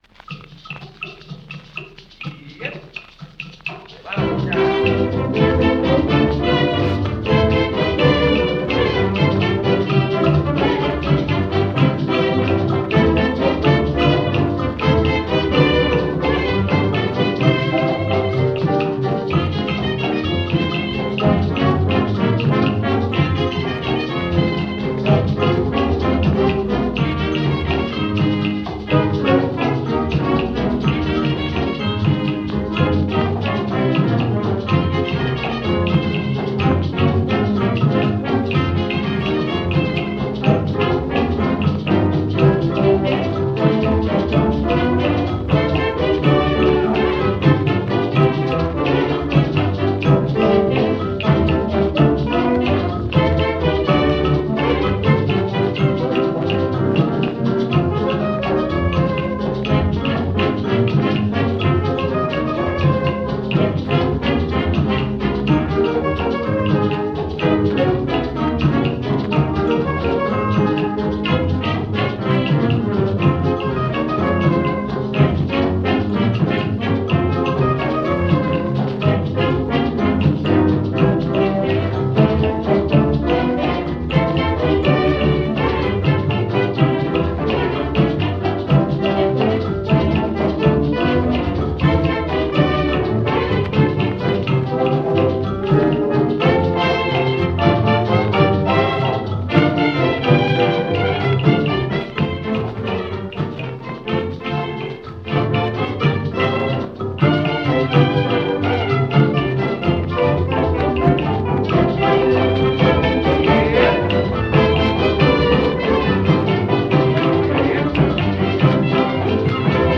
78 rpm